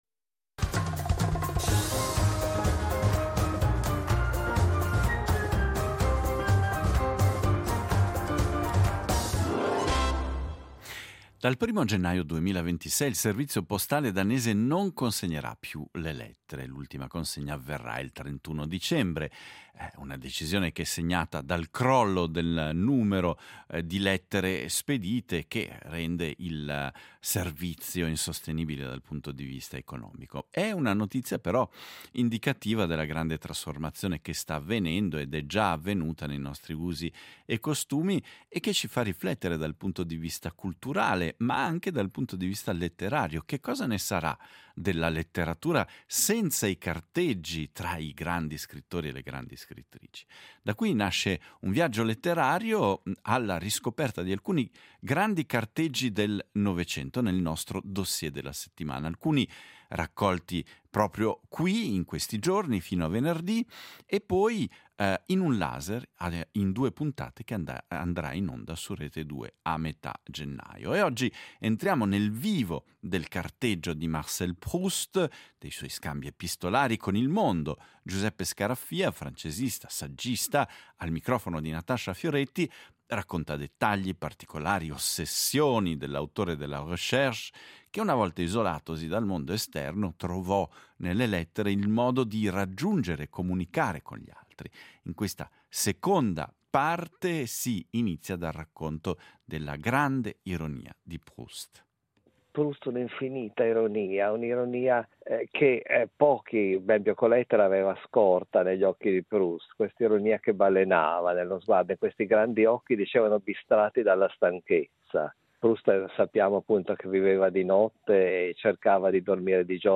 francesista, saggista